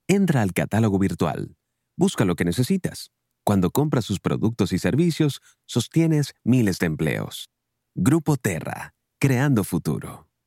Male
From commercials to narration, audiobooks, podcasts, and radio/TV voiceovers, I offer a neutral Spanish accent that fits a wide range of styles. My voice is smooth, professional, and easy to connect with.
Explainer Videos